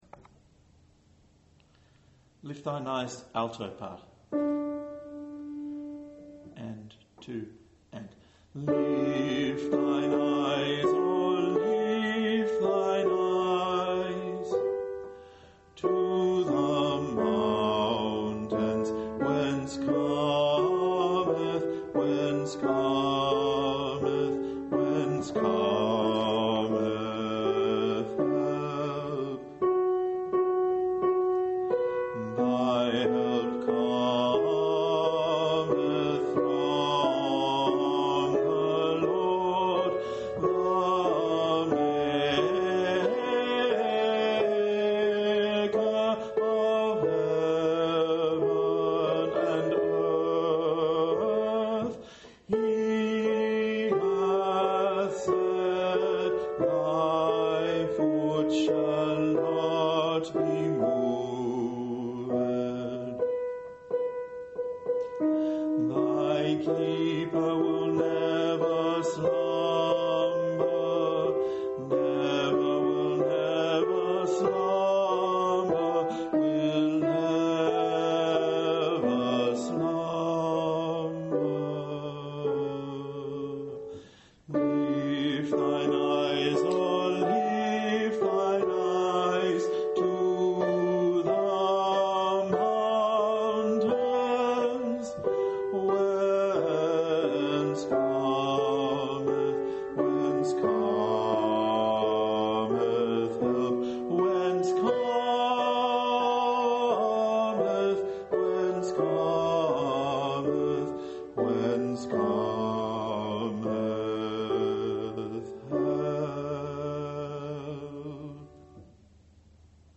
8.-MENDELSSOHN_LiftThineEyes_ALTO.MP3